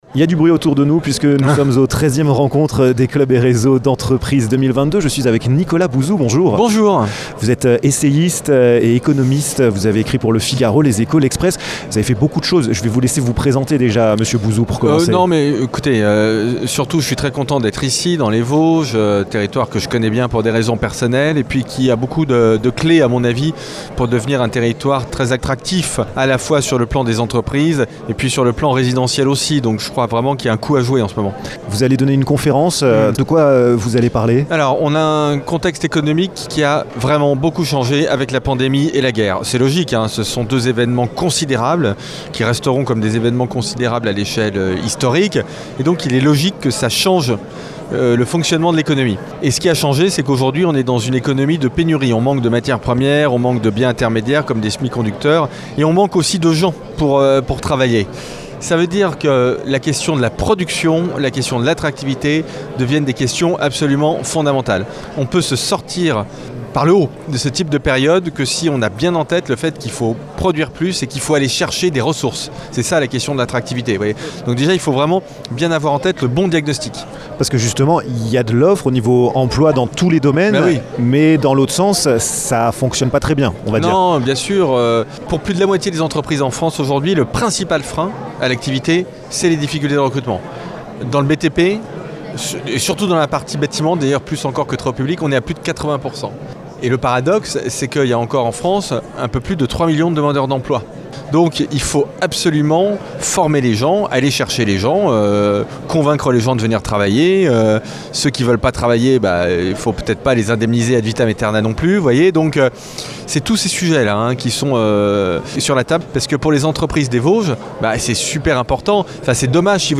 A l'invitation de la CCI Vosges, Nicolas Bouzou (économiste et essayiste) était présent à cette nouvelle rencontre des clubs et réseaux d'entreprises, qui s'est tenue au centre des Congrés d'Epinal.
Il a donné une conférence sur comment penser l'attractivité du territoire et de ses entreprises dans le contexte économique actuel. Nicolas Bouzou vous donne quelques indications dans cette interview.